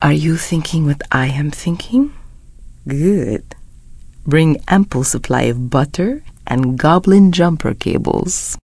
DraeneiFemaleFlirt08.wav